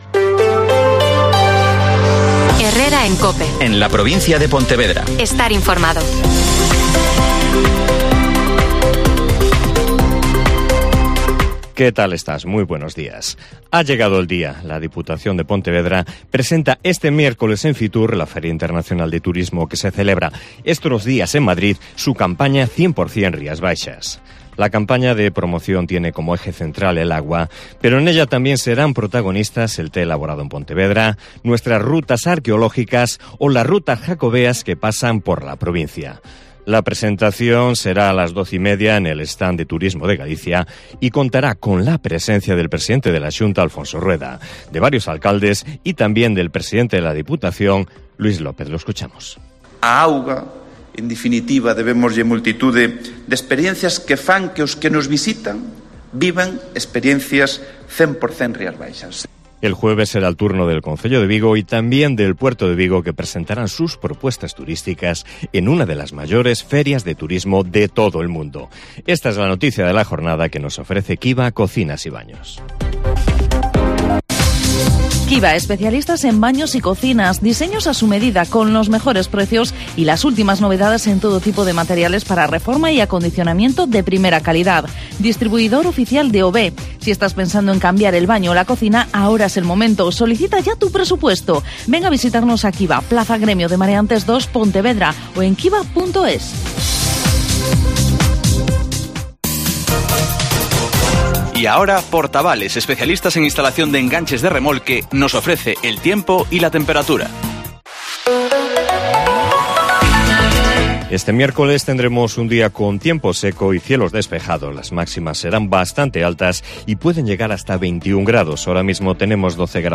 Herrera en COPE en la Provincia de Pontevedra (Informativo 08:24h)